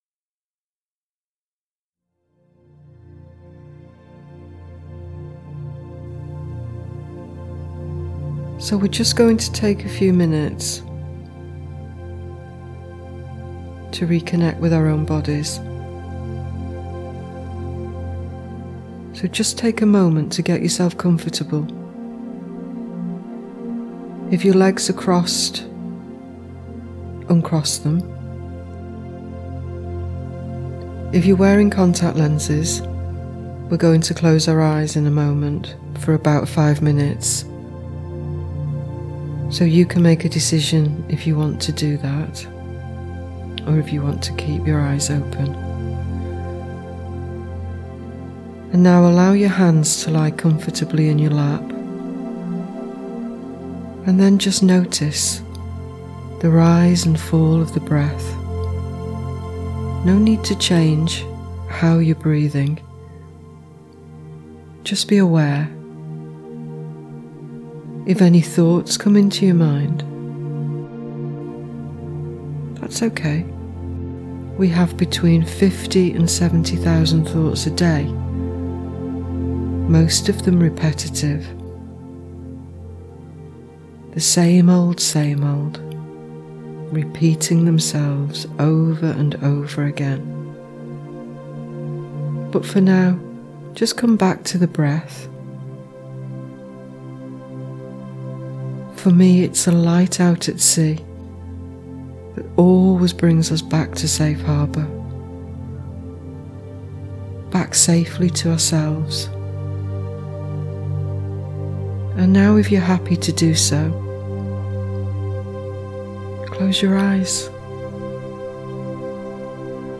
Here is the bodyscan mp3 – I hope you enjoy it!